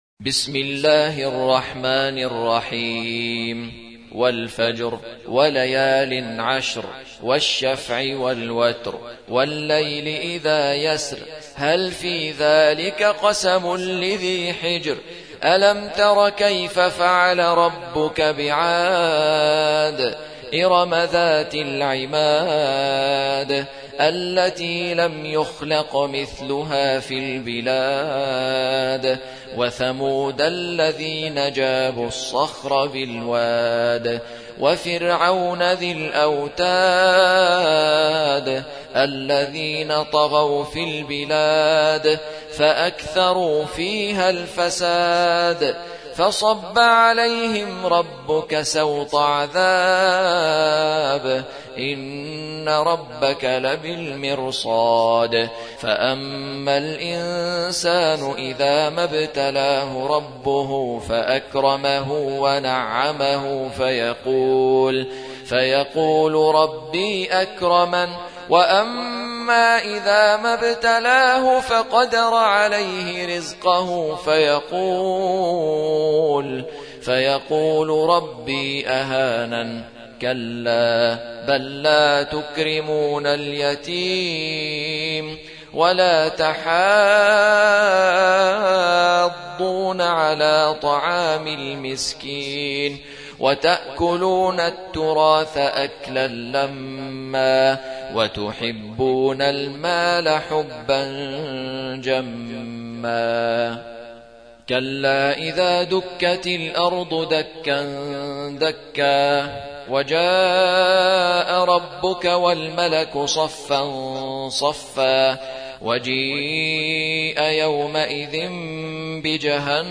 89. سورة الفجر / القارئ